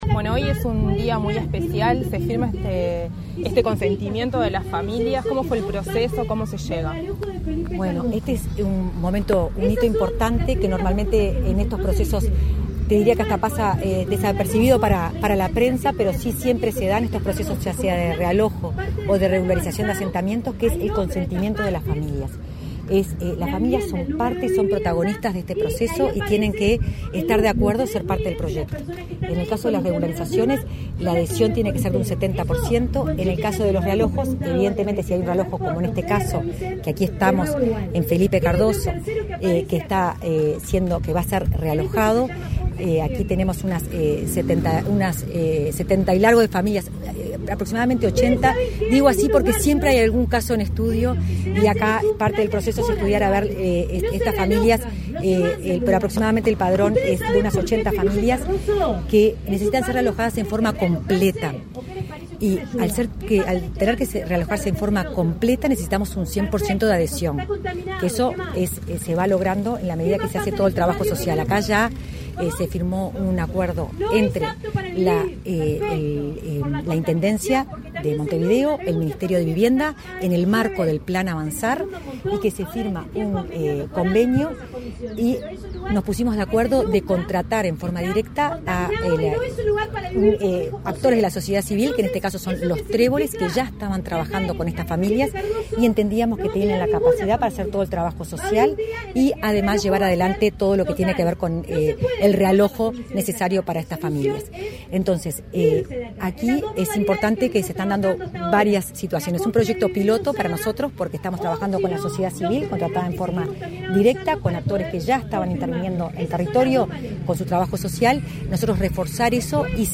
Declaraciones de la directora de Integración Social y Urbana de Vivienda, Florencia Arbeleche
Tras la firma de consentimiento de realojo para familias del barrio Felipe Cardoso, Montevideo, este 19 de diciembre, la directora de Integración